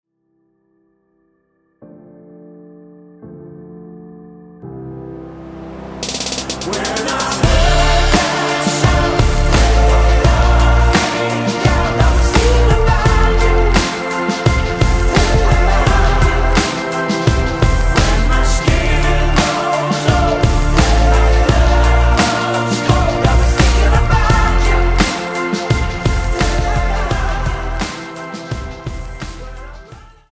--> MP3 Demo abspielen...
Tonart:Dm mit Chor